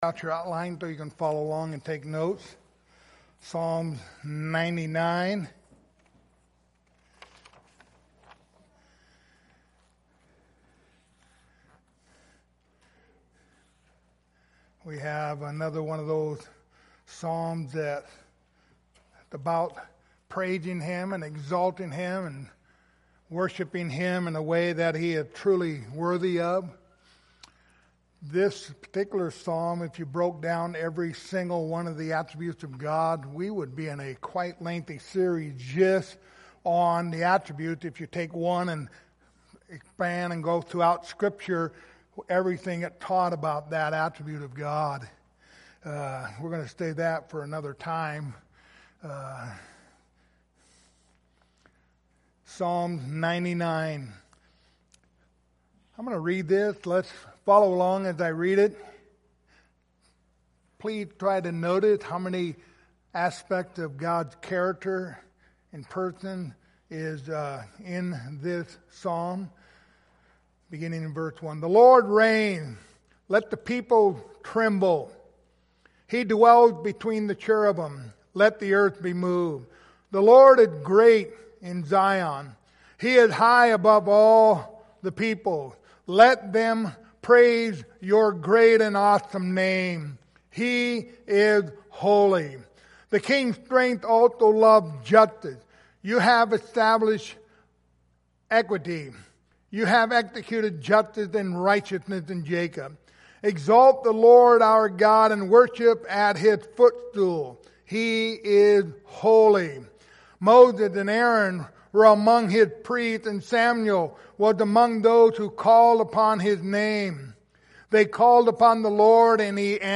The book of Psalms Passage: Psalms 99:1-9 Service Type: Sunday Morning Topics